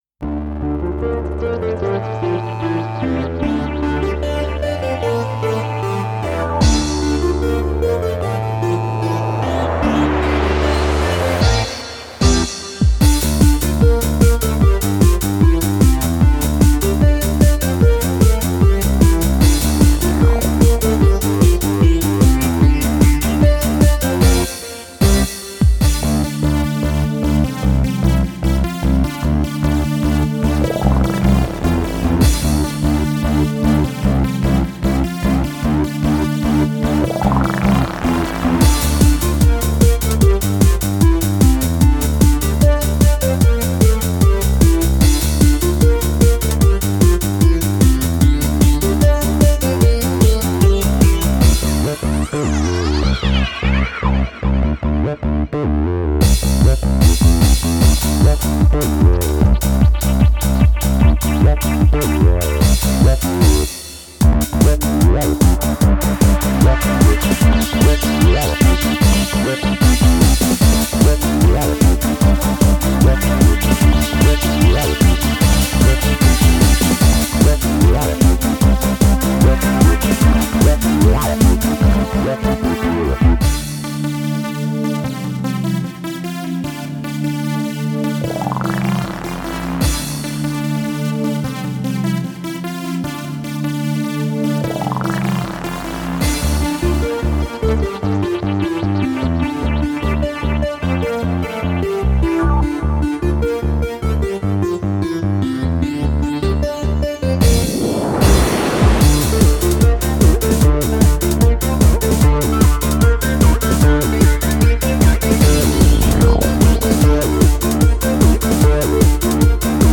Synths meet silicon chips in Brussels